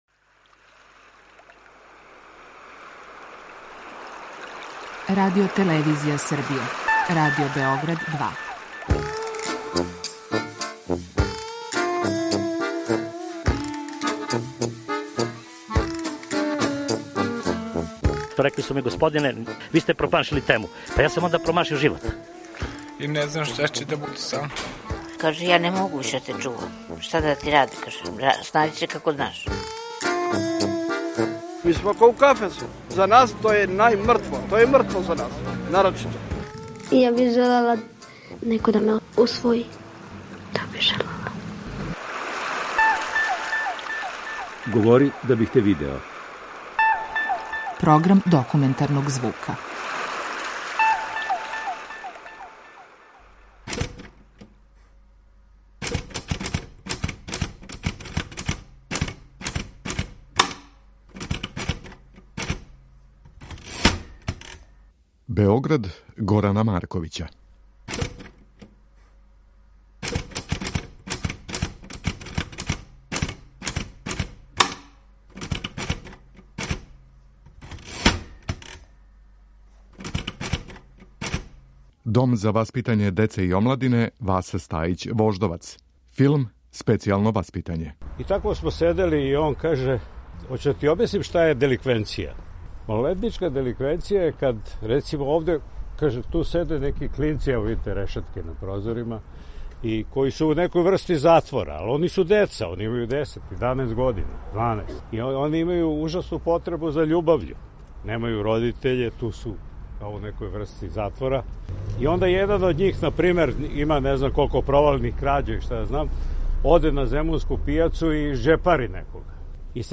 Документарни програм
Звучна репортажа са Фестивала ауторског филма
У оквиру недавно одржаног Фестивала ауторског филма, организована је својеврсна четворочасовна екскурзија по Београду, односно по локацијама на којима су снимани филмови „Специјално васпитање", „Вариола вера", „Мајстори, мајстори", „Сабирни центар", „Национална класа". Вођа пута био је сценариста и редитељ тих остварења Горан Марковић.